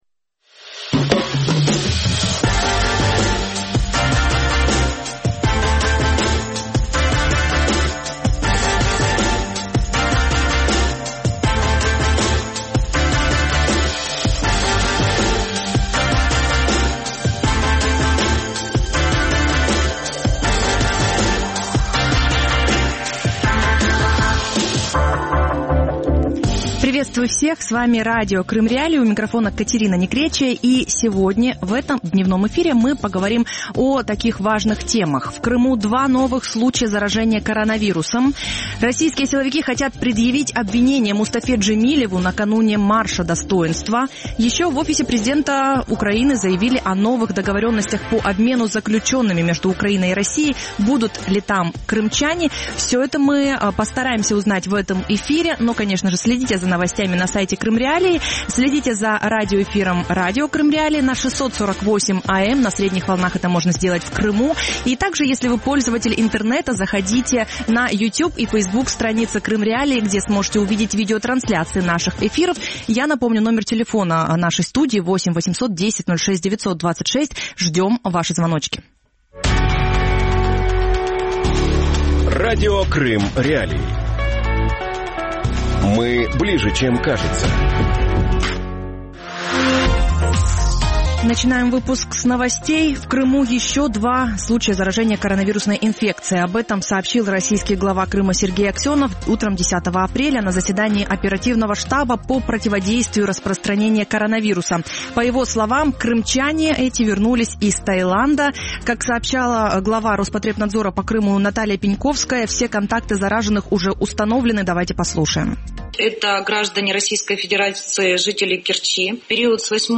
Путин, печенеги и пандемия | Дневное ток-шоу